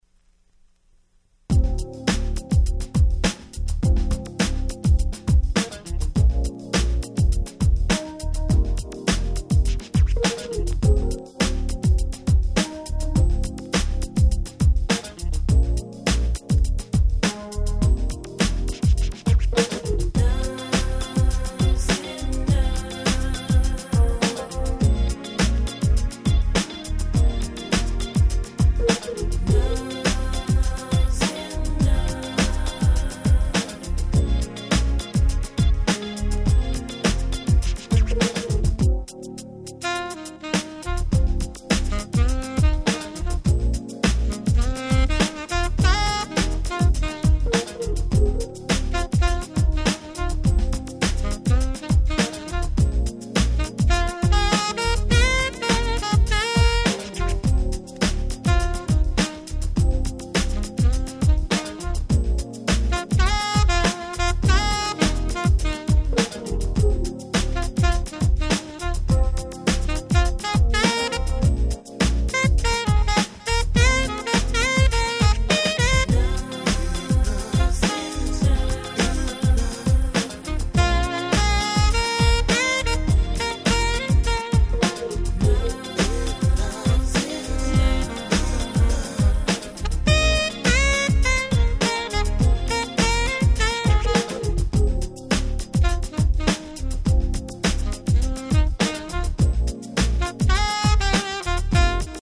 (試聴ファイルにあるプチノイズはこの盤にはございません。)
00' Club Hit R&B♪